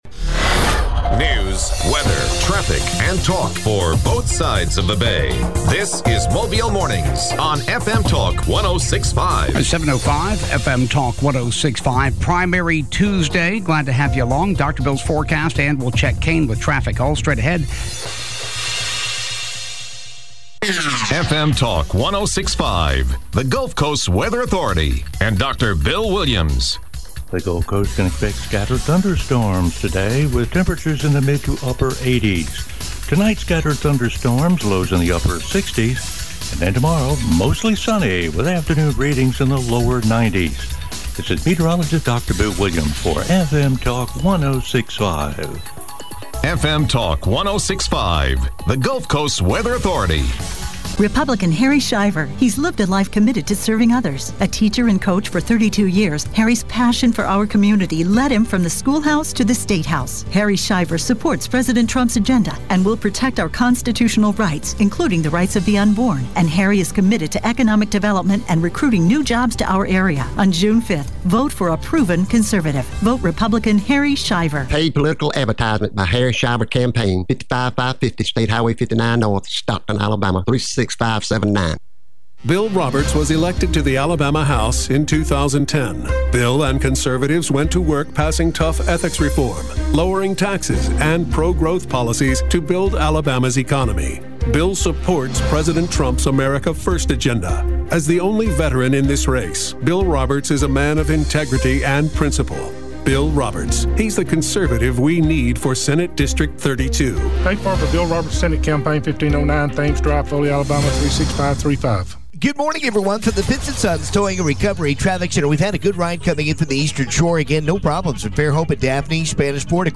Alabama Secretary of State John Merrill interview